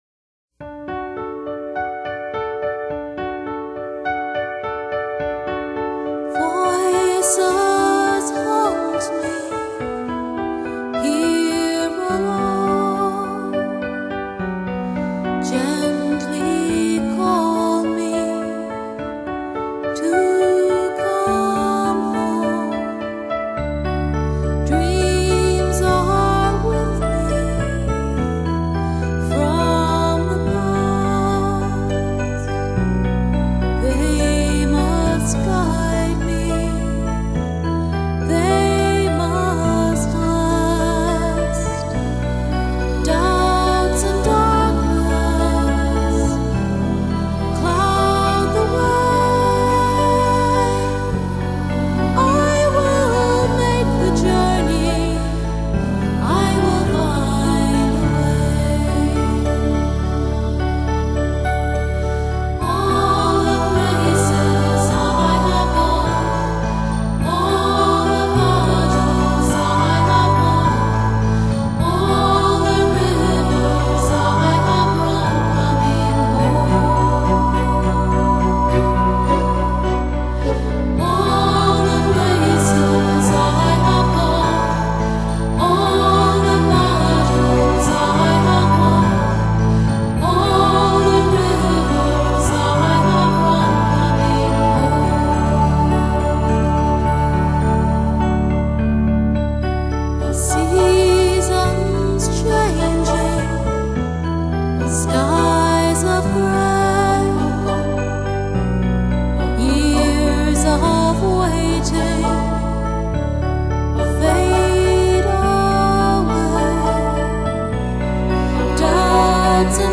音乐流派：新世纪
而优美的女声则是这其中最叫人动容的乐音。